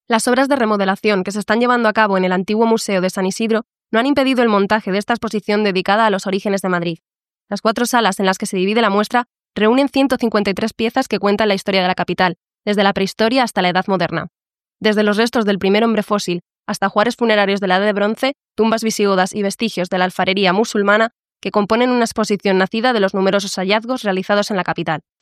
Cultura